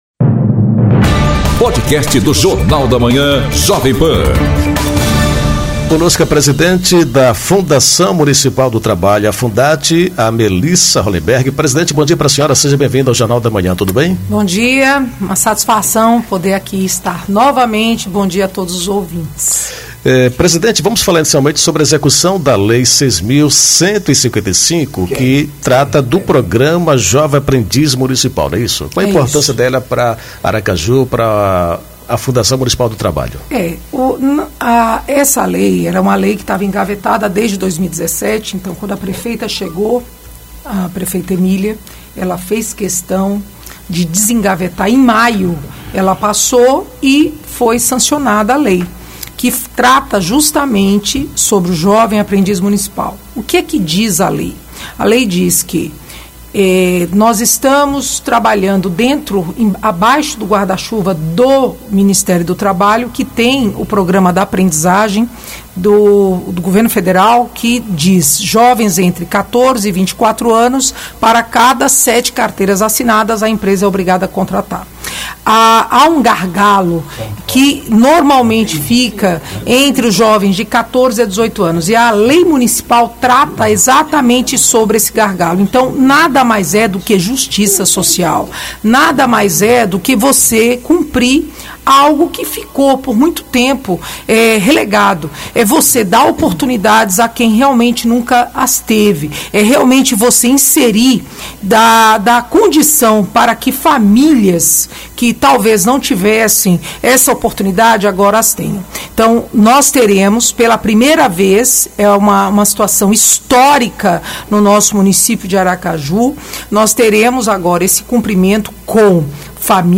Entrevista com Melissa Rolemberg, presidente da Fundat. Ela fala sobre o projeto do Menor Aprendiz